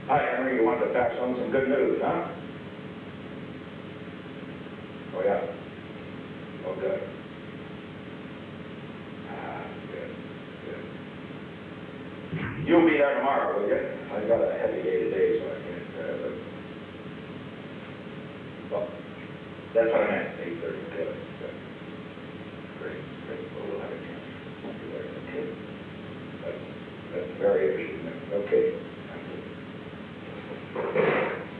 Secret White House Tapes
Conversation No. 442-41
Location: Executive Office Building
The President talked with Henry A. Kissinger.